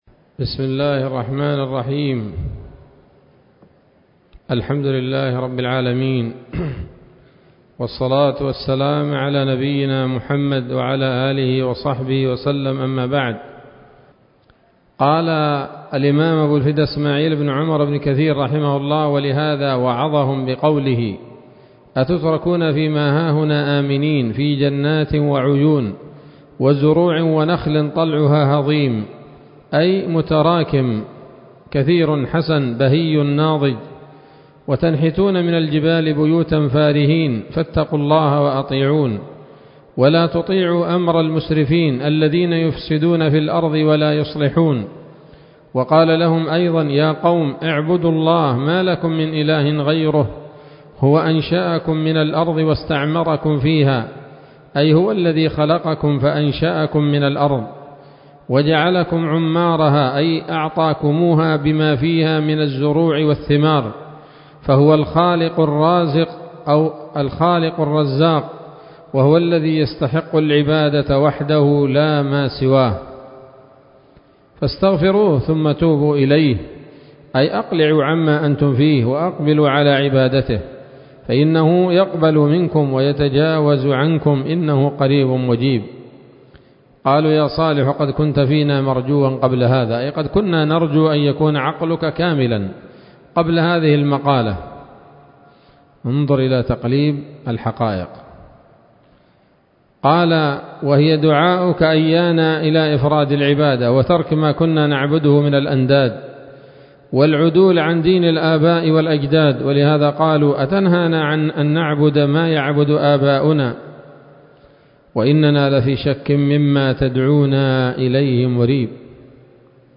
الدرس الخامس والثلاثون من قصص الأنبياء لابن كثير رحمه الله تعالى